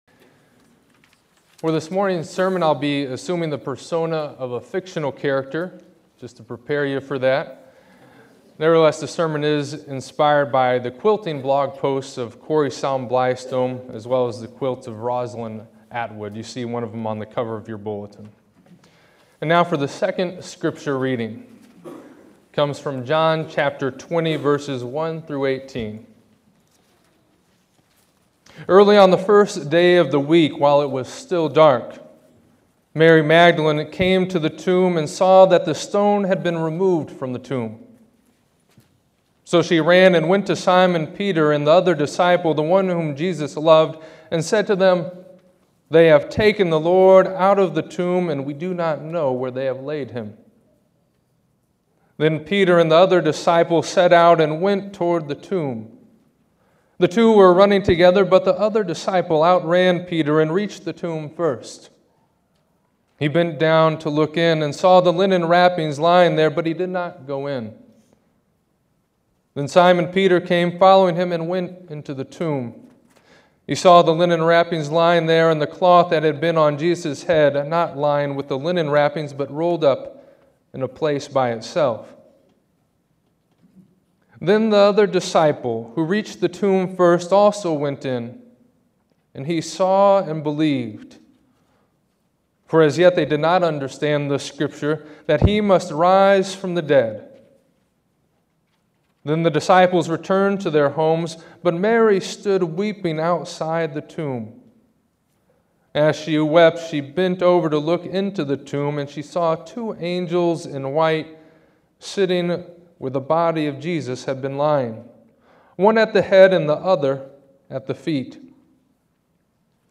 To listen to this Easter sermon, click here.